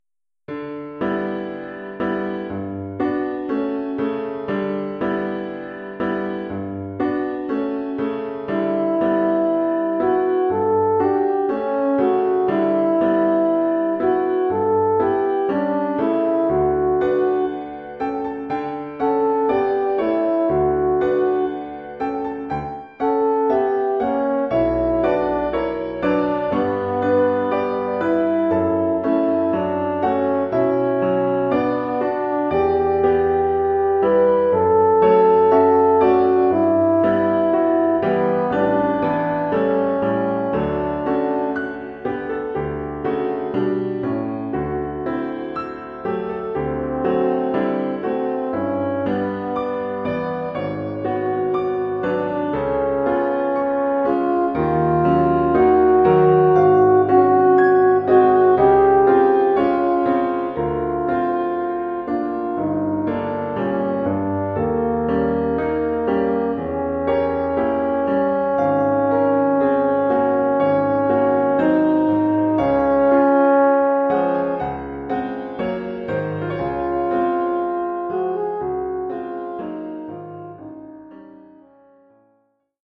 Oeuvre pour cor d’harmonie et piano.
Niveau : élémentaire.